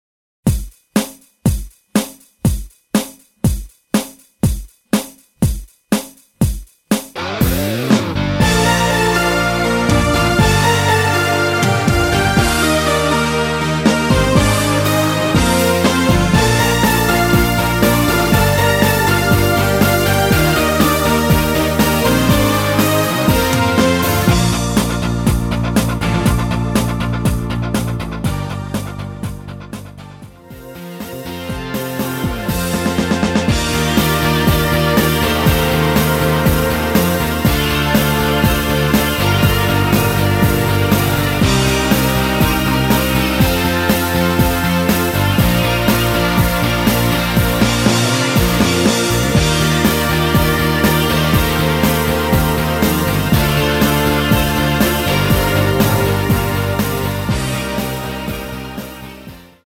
대부분의 여성분이 부르실수 있는 키 입니다.
원곡의 보컬 목소리를 MR에 약하게 넣어서 제작한 MR이며